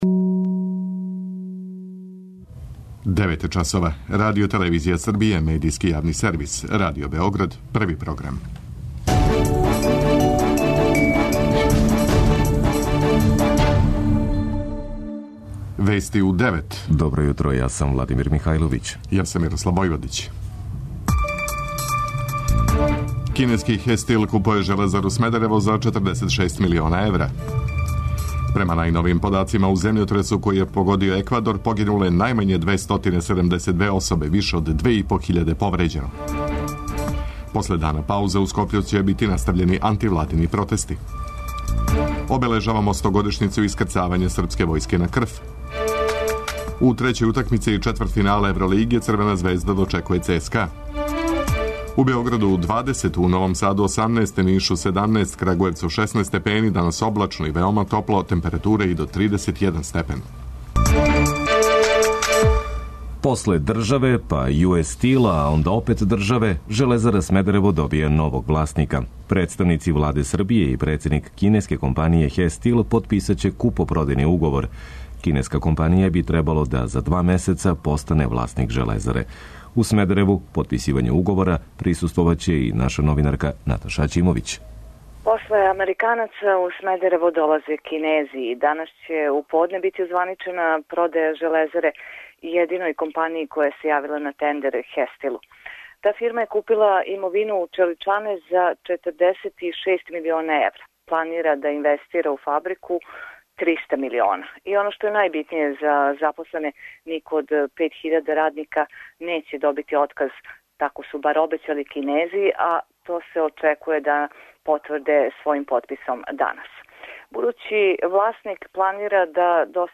преузми : 10.02 MB Вести у 9 Autor: разни аутори Преглед најважнијиx информација из земље из света.